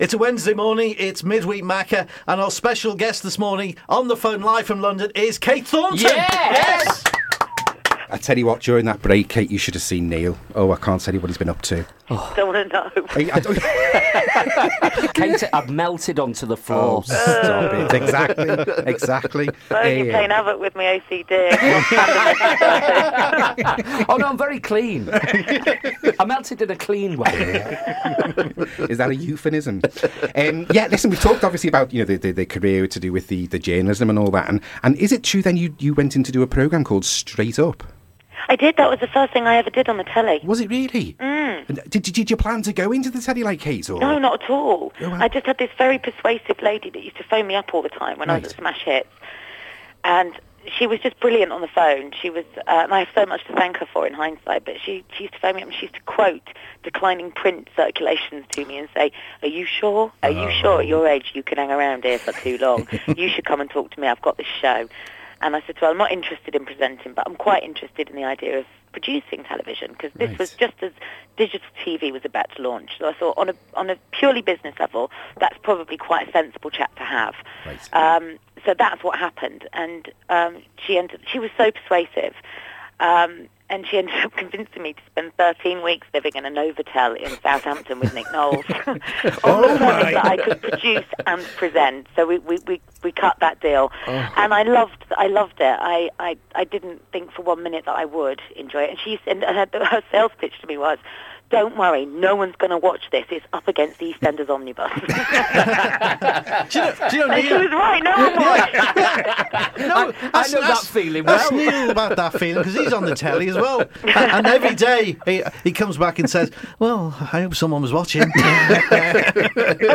Midweek Macca, part of the Wirral Radio Breakfast Show.